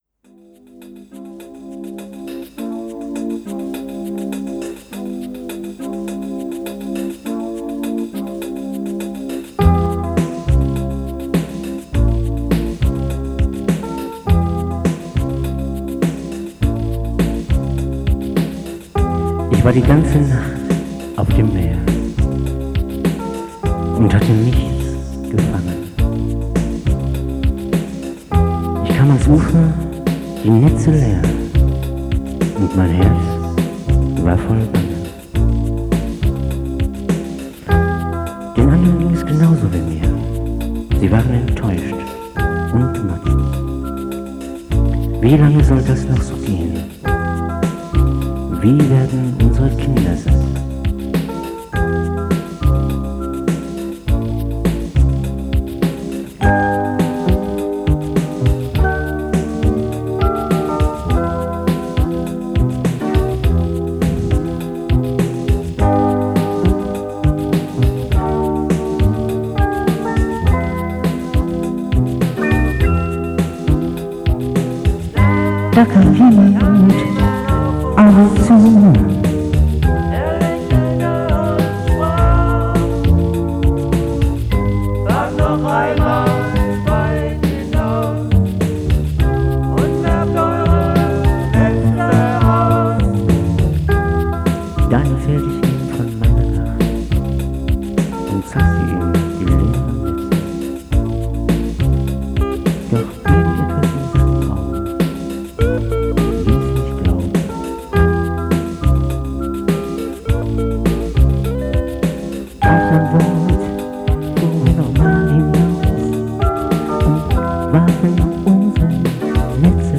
alle Instrumente und Gesang